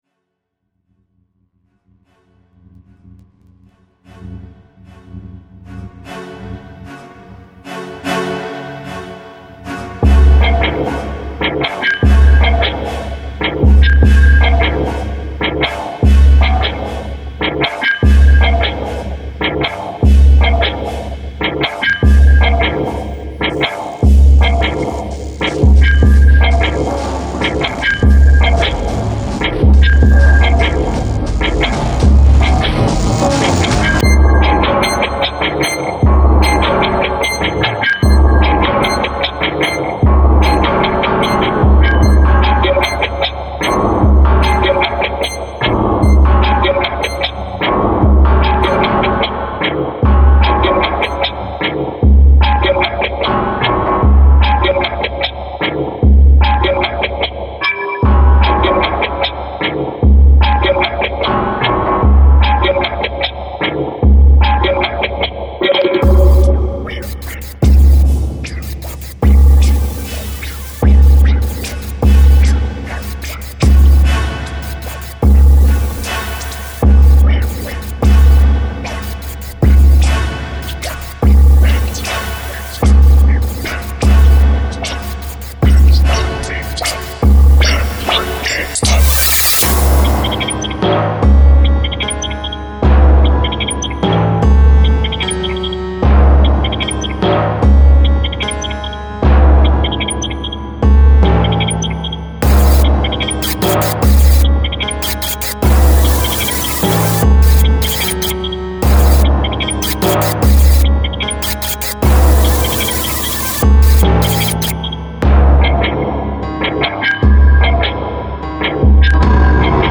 ホラーな雰囲気で、ちょっとアンビエントっぽい感じを目指しました。
SEやボイスを使っています。
Tags: インスト , シリアス